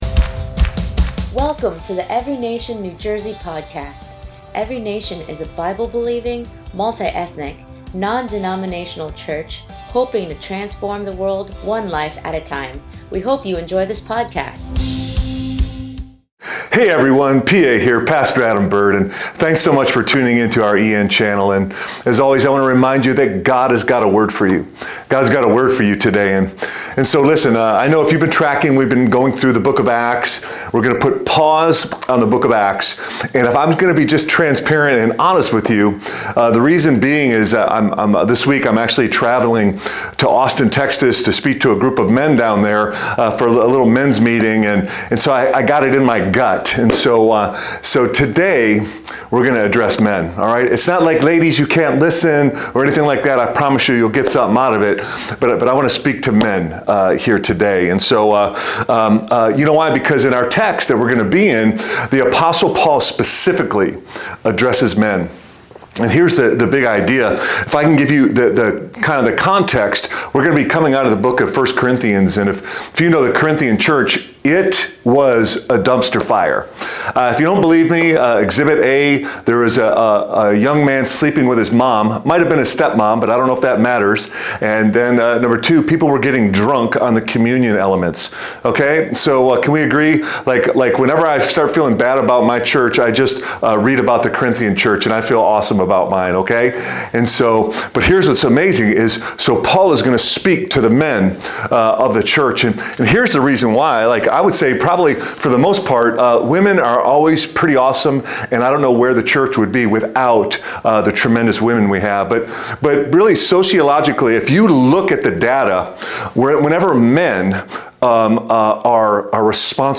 2025 A CHALLENGE FOR MEN Preacher